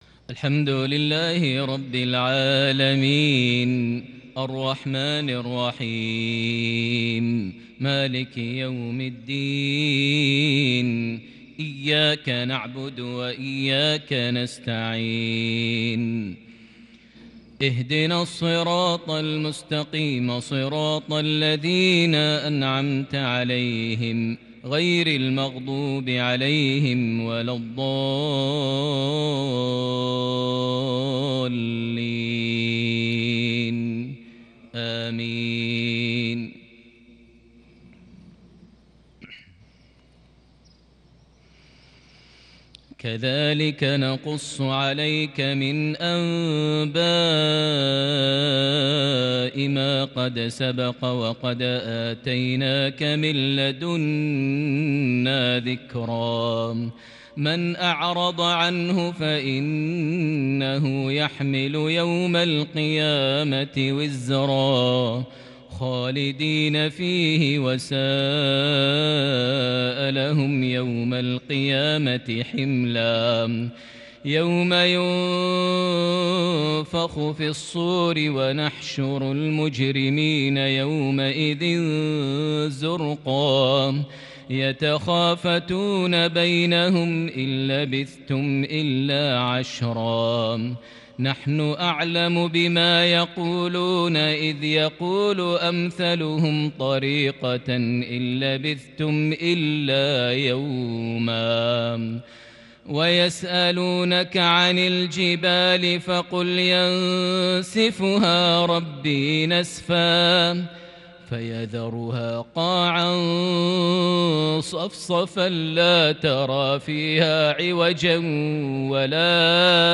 تلاوة فاقت الوصف من سورة طه (99-114) | مفرب 25 صفر 1442هـ > 1442 هـ > الفروض - تلاوات ماهر المعيقلي